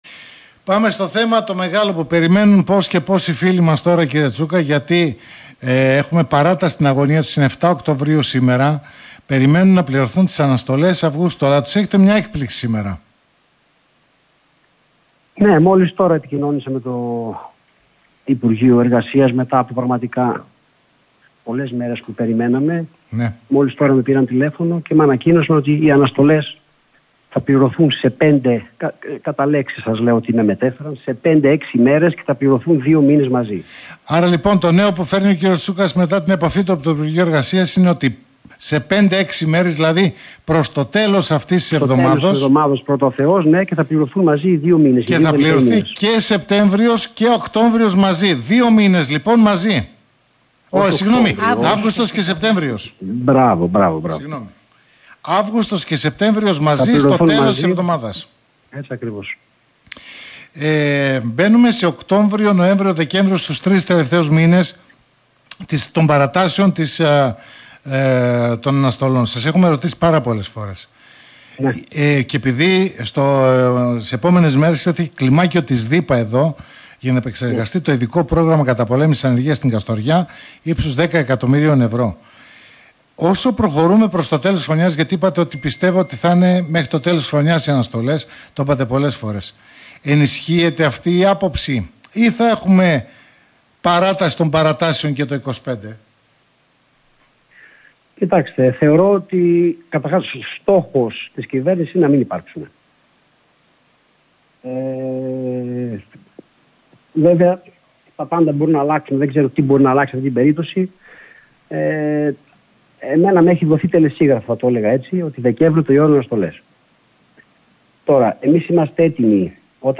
Πότε πληρώνονται οι αναστολές Αυγούστου και Σεπτεμβρίου (συνέντευξη)